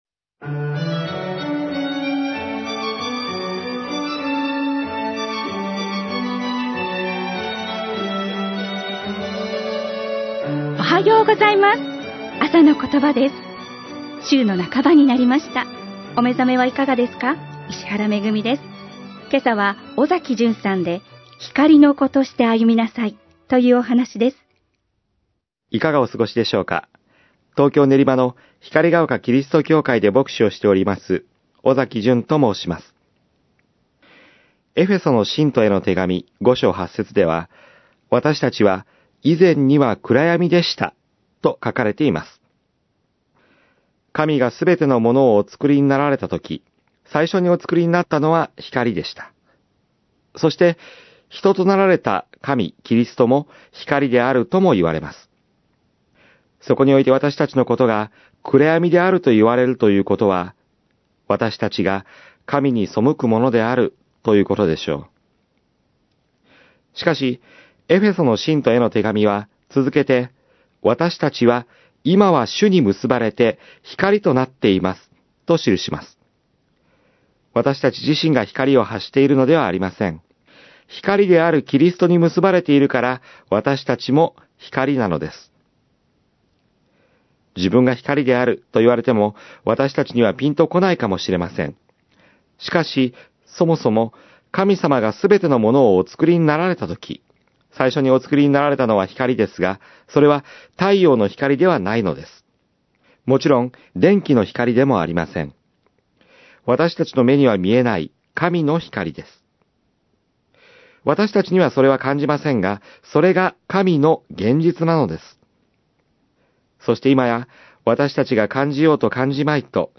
メッセージ： 光の子として歩みなさい